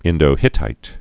(ĭndō-hĭtīt)